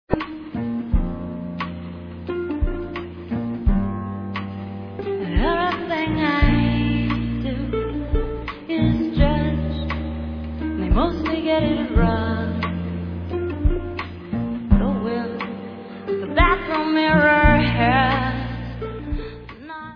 sledovat novinky v oddělení Folk